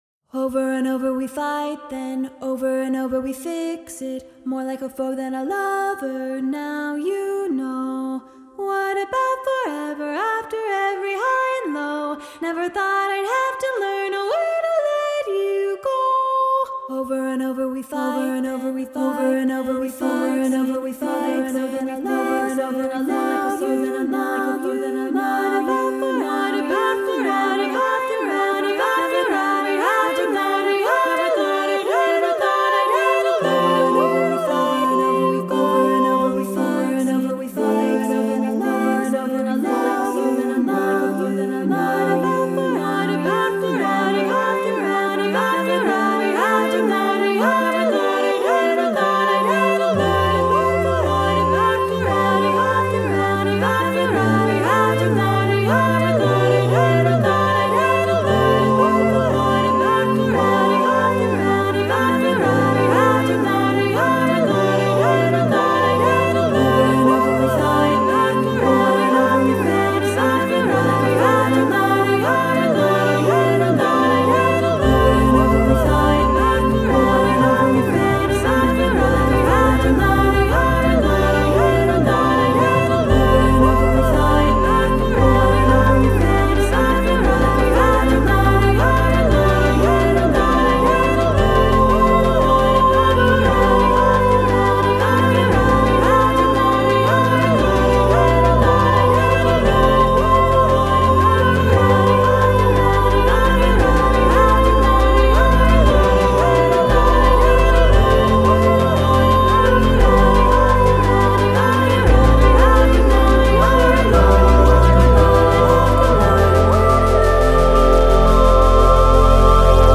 (performed by the composer).
A catchy piece built around canonic ideas and a synthesizer.
for SSAA choir with TBB and/or synth
Canonic and catchy, this song is easy to learn.